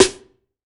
SNARE 090.wav